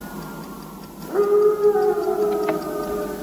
wolf howling sound effect 2.ogg
Original creative-commons licensed sounds for DJ's and music producers, recorded with high quality studio microphones.
[wolf-howling-sound-effect]-3_7ov.mp3